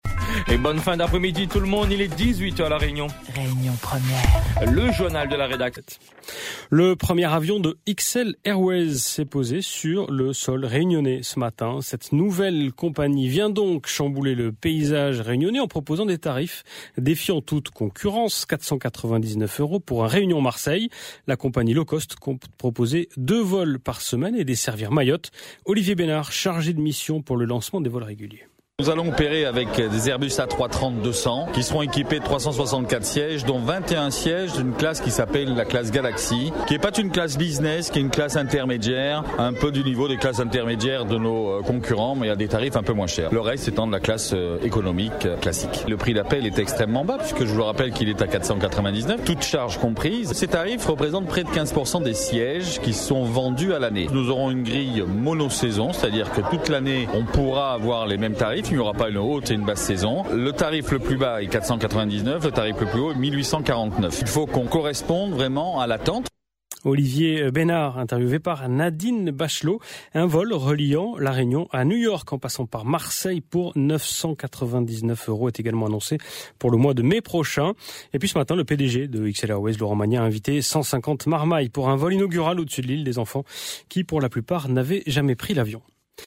Journal de 18h de Radio Réunion Première.mp3 (1.32 Mo)